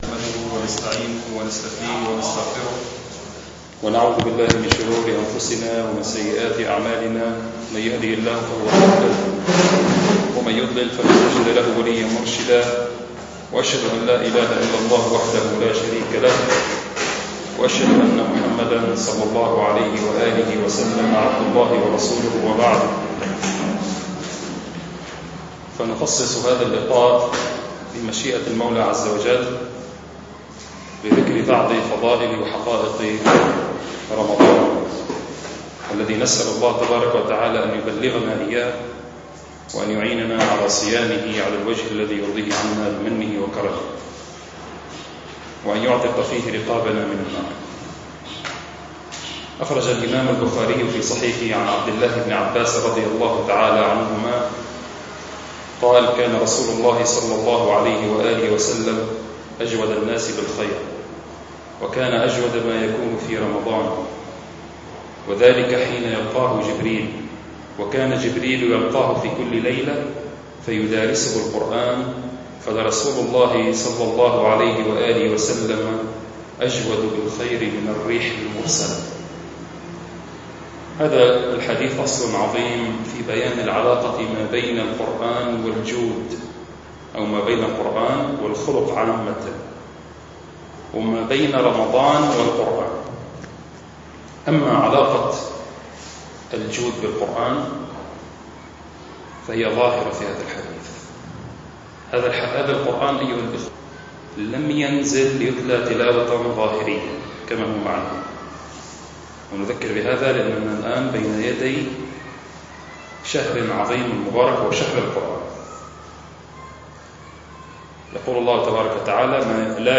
المكان : مبنى جماعة عباد الرحمن